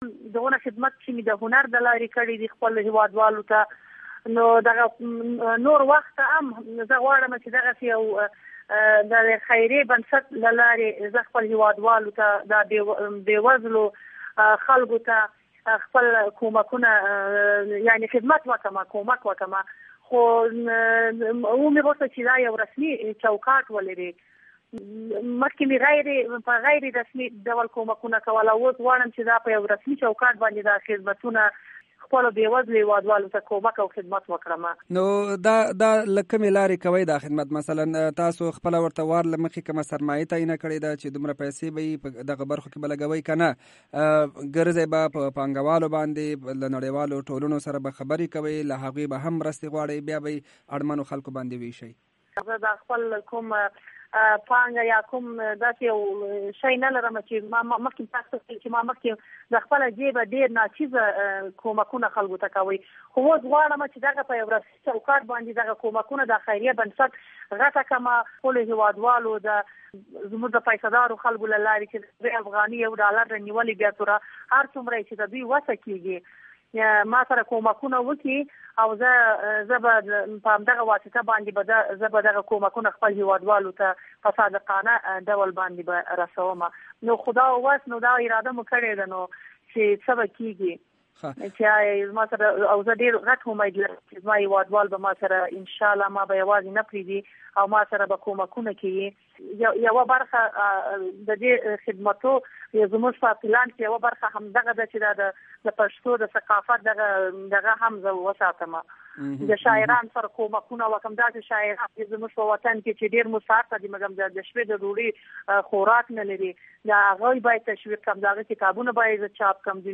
بشپړه مرکه